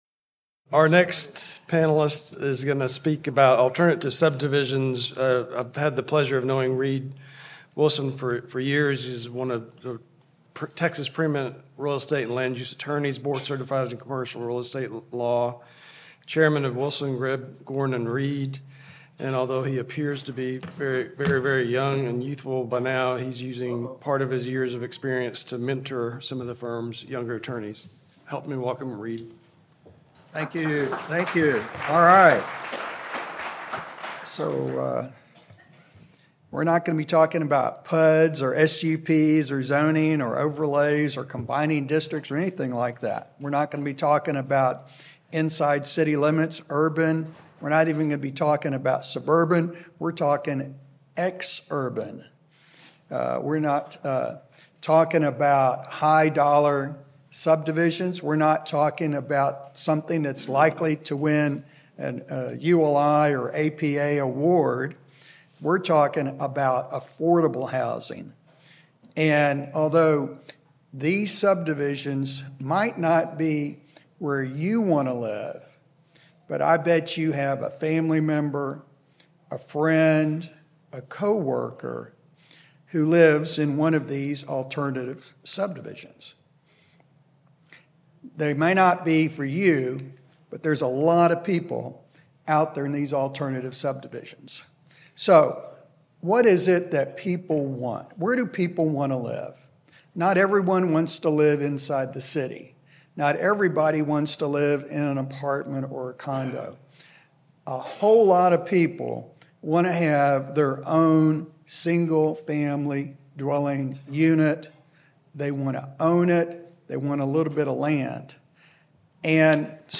Originally presented: Apr 2024 Land Use Conference